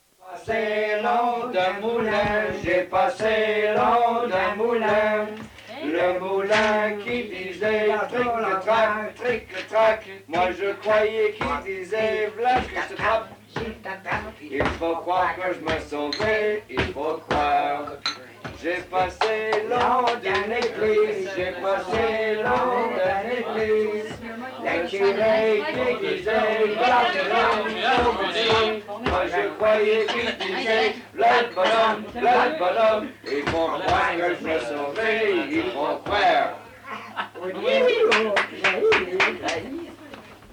Chanson Item Type Metadata
Emplacement L'Anse-aux-Canards